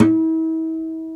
NYLON E 3 HM.wav